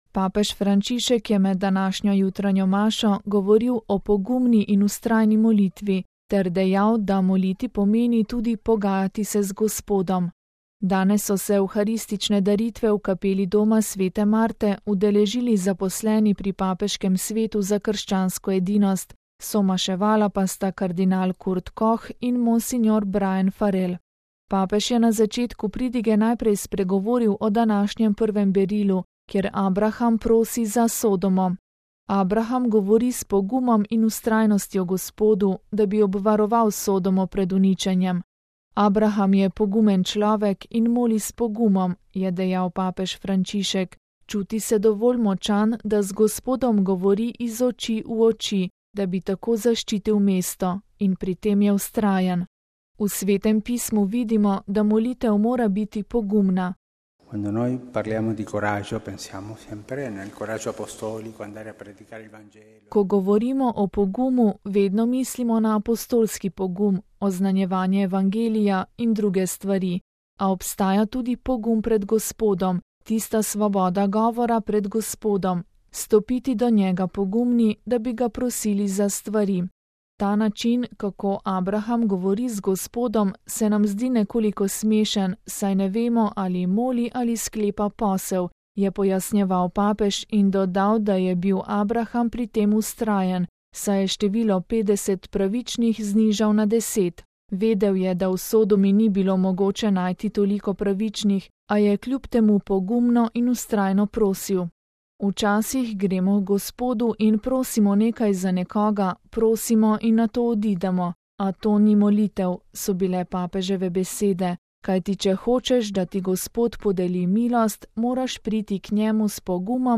Papež Frančišek med današnjo jutranjo mašo: Pogumno in vztrajno moliti. Molitev je tudi pogajanje z Bogom